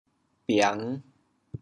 biang5.mp3